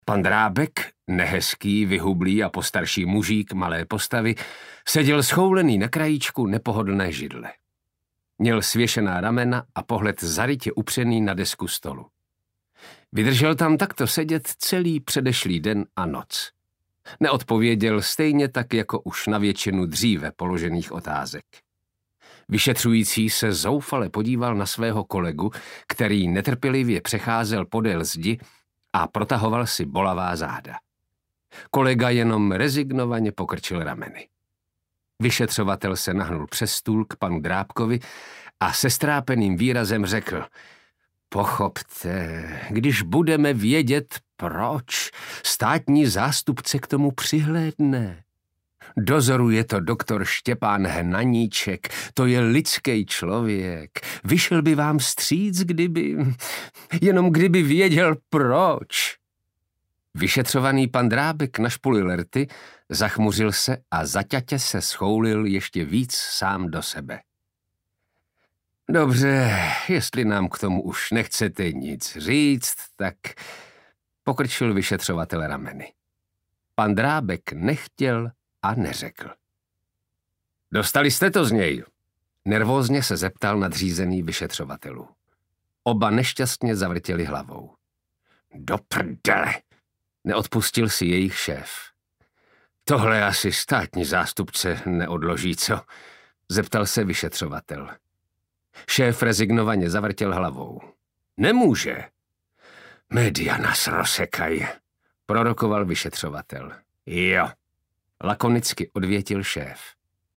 Zloději času audiokniha
Ukázka z knihy
zlodeji-casu-audiokniha